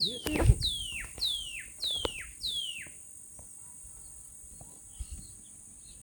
Angú (Donacobius atricapilla)
Nombre en inglés: Black-capped Donacobius
Localidad o área protegida: Concepción del Yaguareté Corá
Condición: Silvestre
Certeza: Fotografiada, Vocalización Grabada